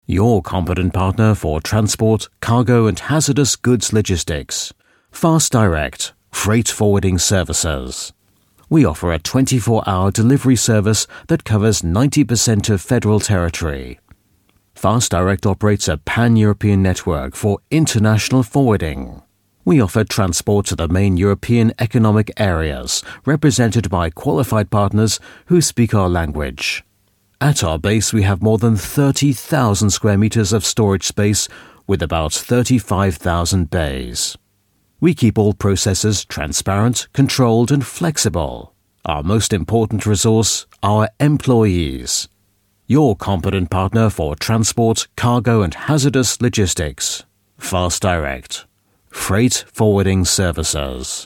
Native Speaker
Englisch (UK)
Imagefilme